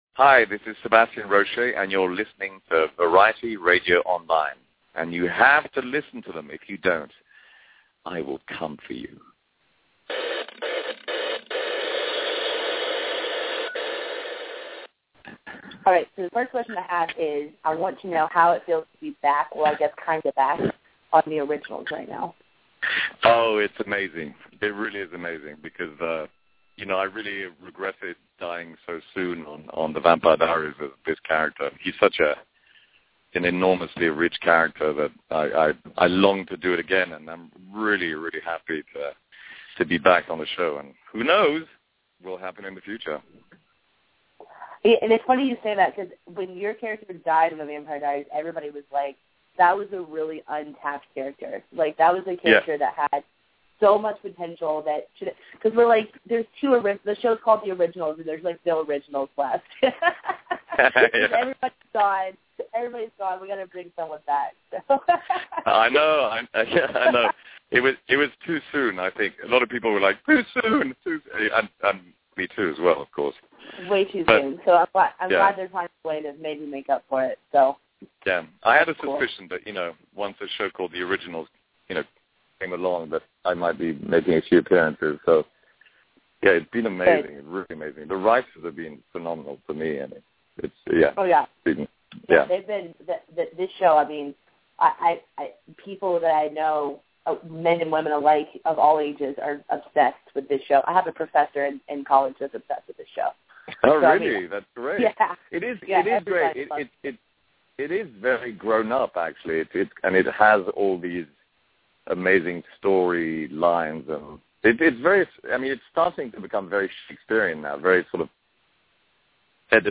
Monday, May 5th Sebastian Roché calls in to talk about his role Mikael in the TV Series ‘The Originals’ that air’s on the CW network.